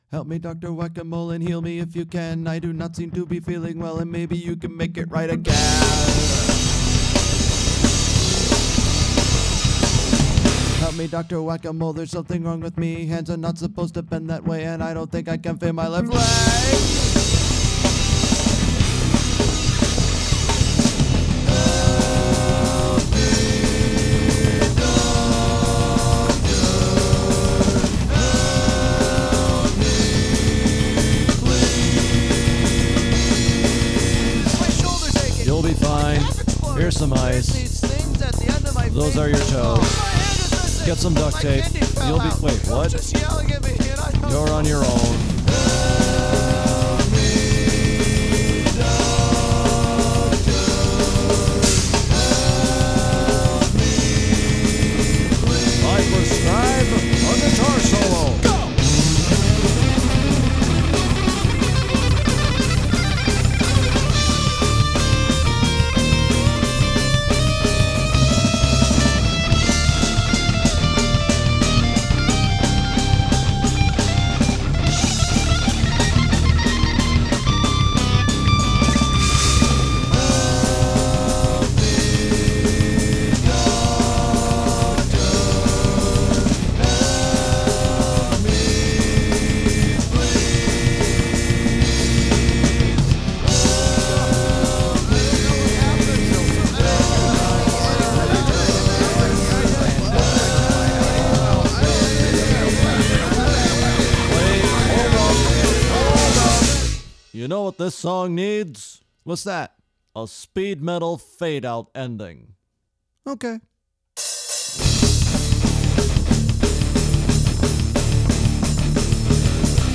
The stacking guitars at the beginning, the guitars dropping out for the second verse, the speed metal parts, the borderline-atonal chorus in 5 (needless to say, tracking the chorus vocal parts was not easy)… it just all made sense.  I wanted this song to be really uptempo and kinda hyper… and the quickness in which it was written helped accomplish that.
The voices over the last chorus were improvised (no surprise there).